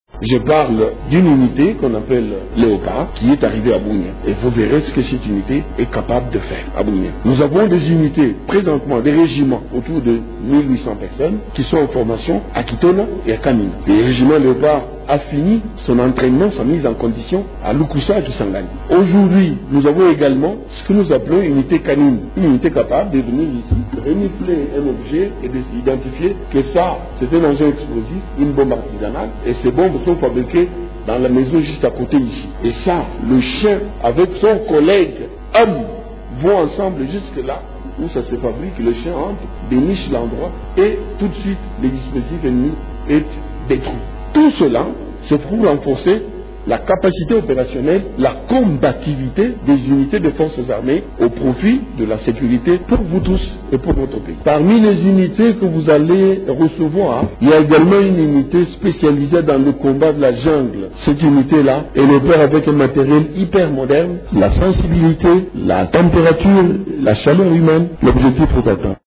Le général Léon-Richard Kasonga répond aux questions